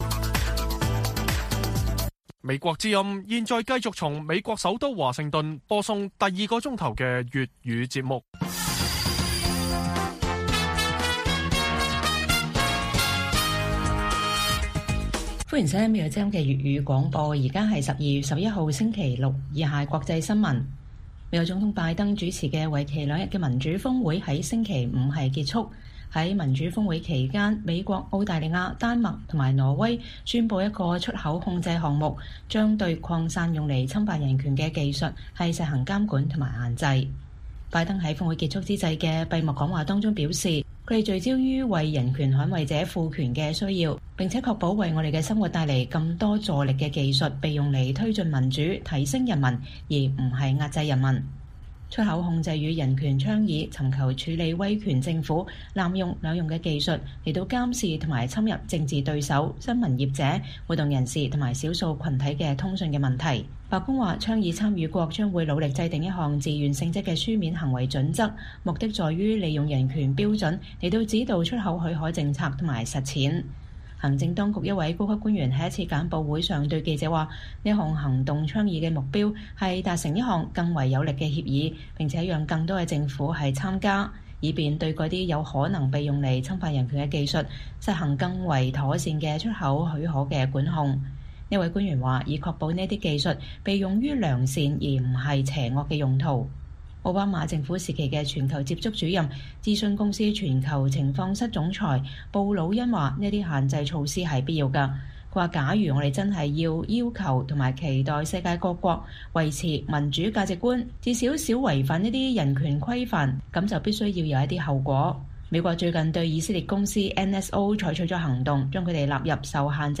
粵語新聞 晚上10-11點: 美澳丹挪宣佈限制輸出用於壓迫的網絡工具